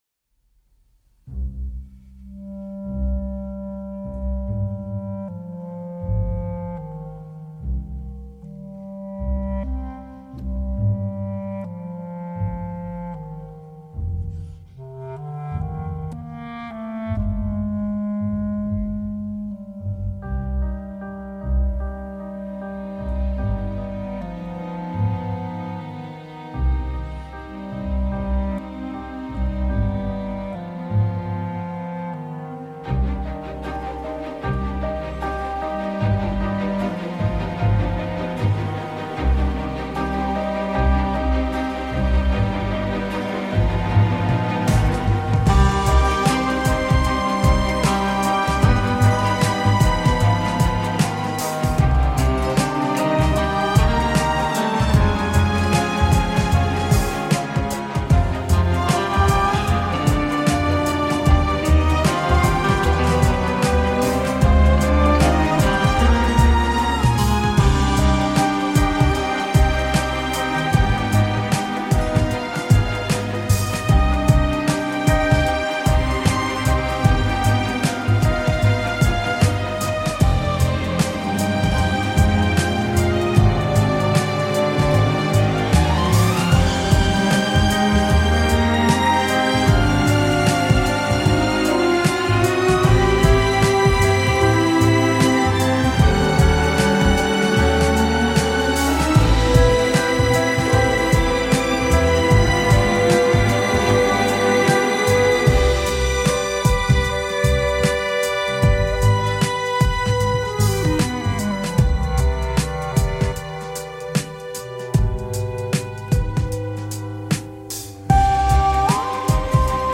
Si la musique est principalement orchestrale
n’hésitant pas à mêler à l’orchestre des rythmiques hip-hop.
Ludique, sympa, pleine de fantaisie et d’idées musicales
sait aussi se faire douce et lyrique